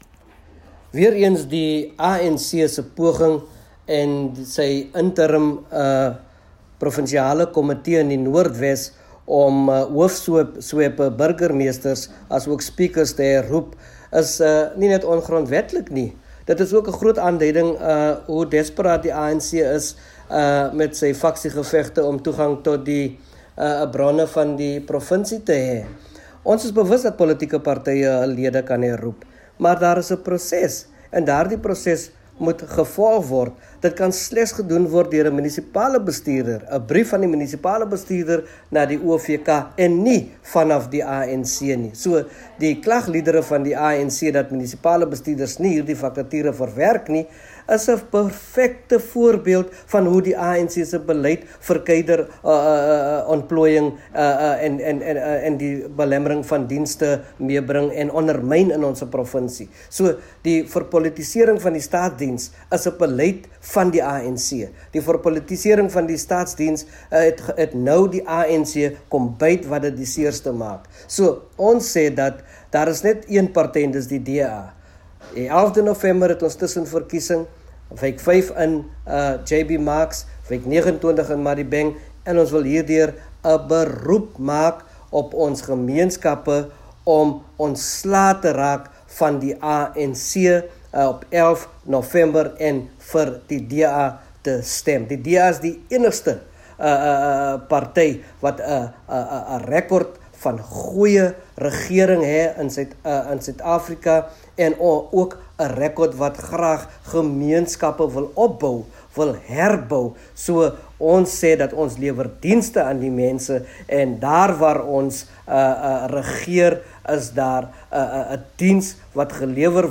Afrikaans by Joe McGluwa MP, DA North West Provincial Leader